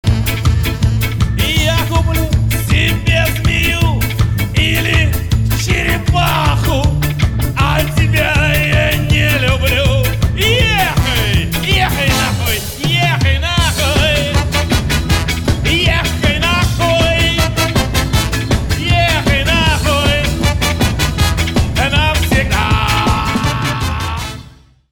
• Качество: 320, Stereo
мужской вокал
Саксофон
русский рок